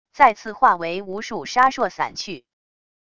再次化为无数沙硕散去wav音频生成系统WAV Audio Player